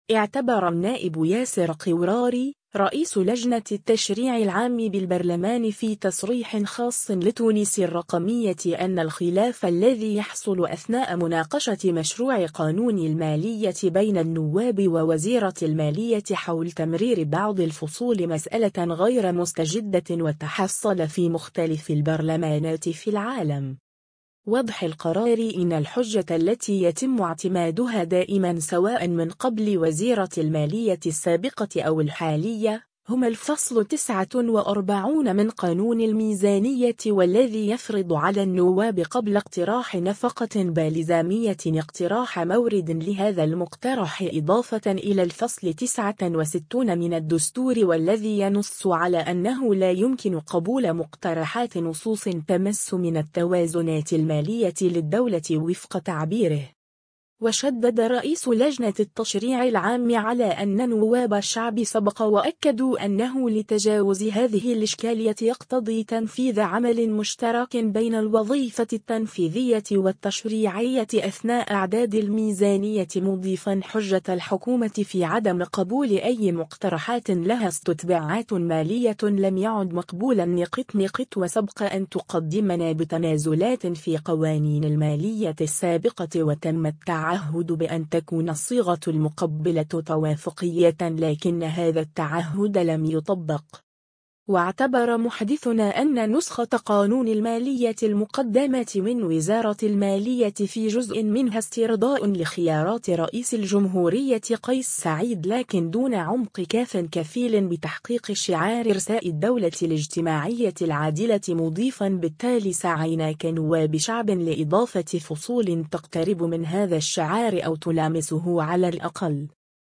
اعتبر النائب ياسر قوراري ، رئيس لجنة التشريع العام بالبرلمان في تصريح خاص لـ”تونس الرقمية” أن الخلاف الذي يحصل أثناء مناقشة مشروع قانون المالية بين النواب ووزيرة المالية حول تمرير بعض الفصول مسألة غير مستجدة وتحصل في مختلف البرلمانات في العالم.